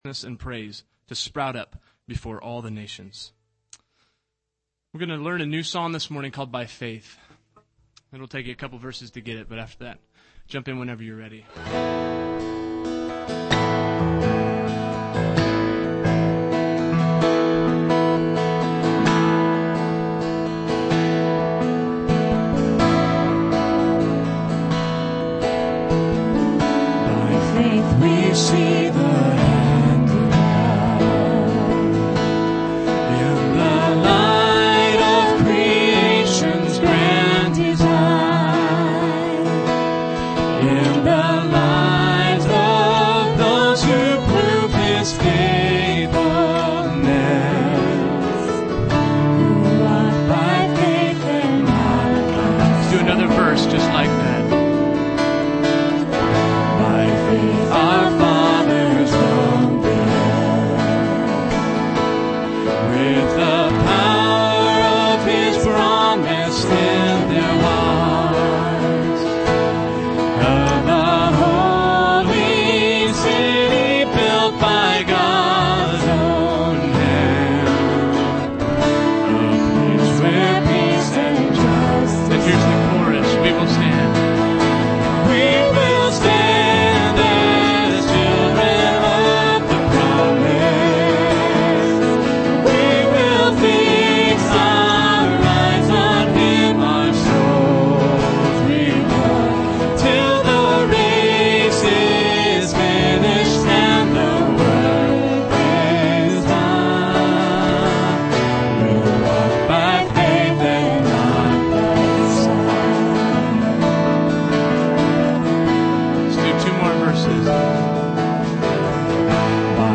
December 11, 2011 (Sunday Morning)